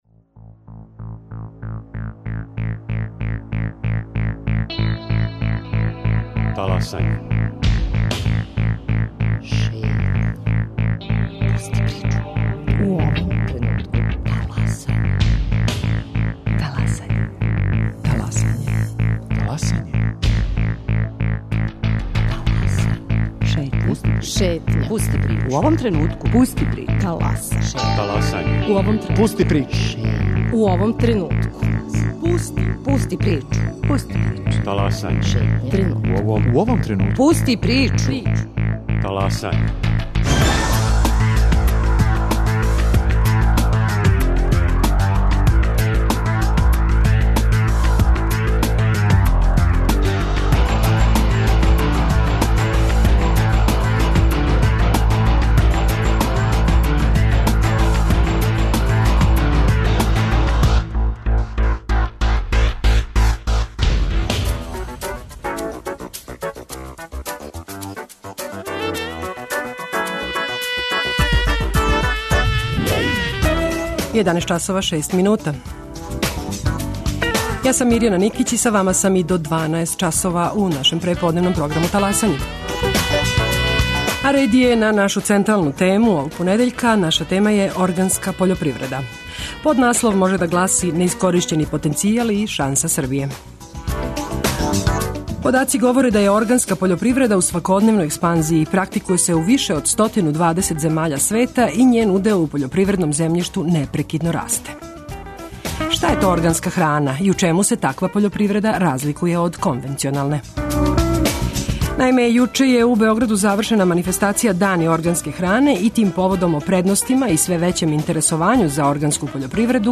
Јуче је у Београду завршена манифестација "Дани органске хране", и тим поводом о предностима и све већем интересовању за органску пољопривреду говоре стручњаци из Министарства за пољопривреду, Националне организације Serbia organica и произвођачи.